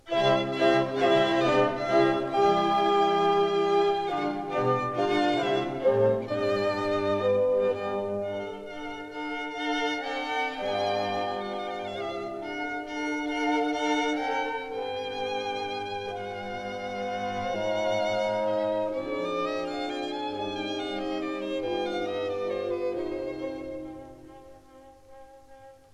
This is a 1959 stereo recording